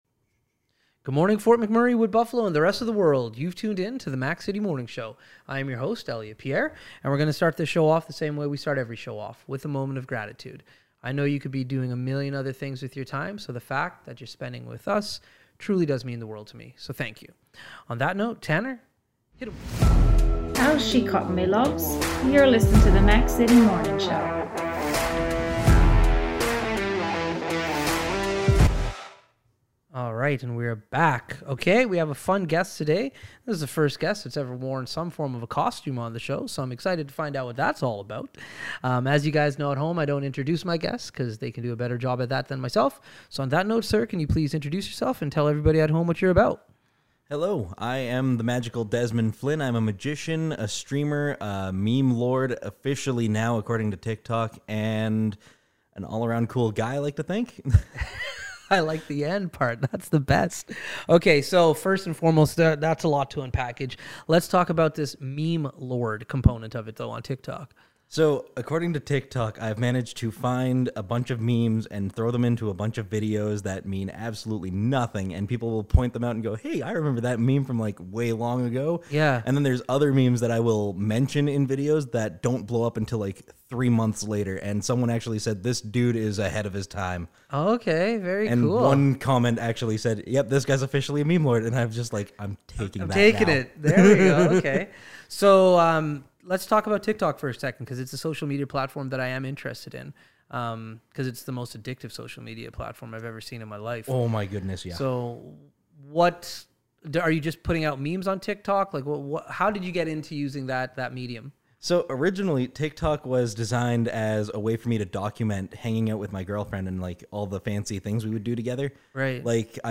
Tune in for a magical conversation!